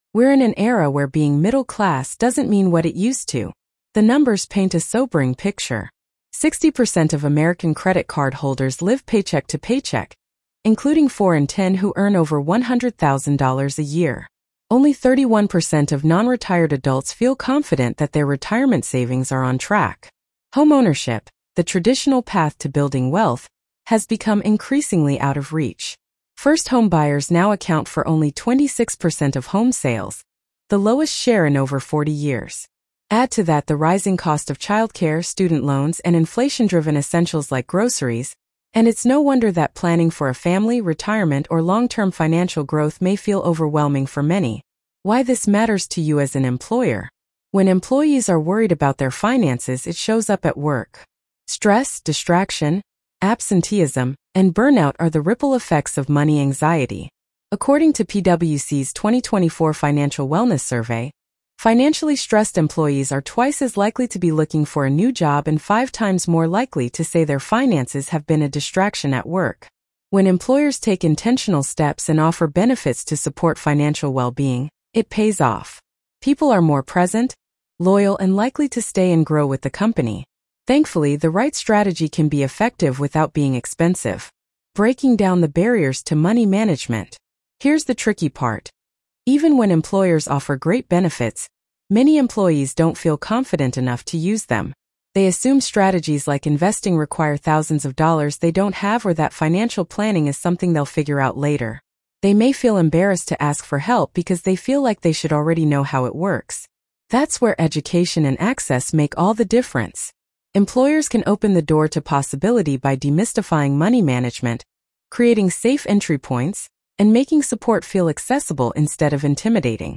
Why Your Benefits Plan Needs to Start Talking About Money Blog Narration.mp3